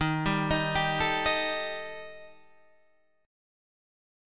D# Major Flat 5th